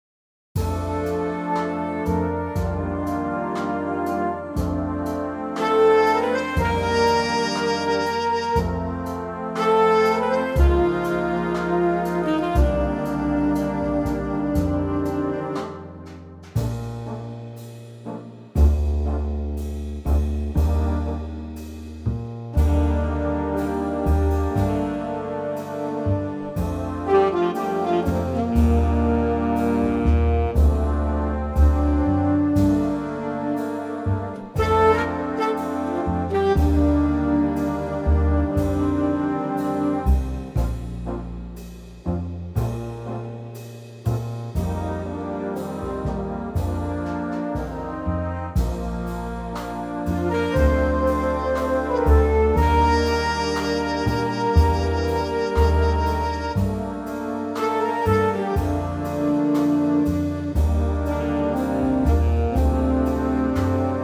Unique Backing Tracks
key - Bb - vocal range - Bb to Eb
Superb mellow Big Band arrangement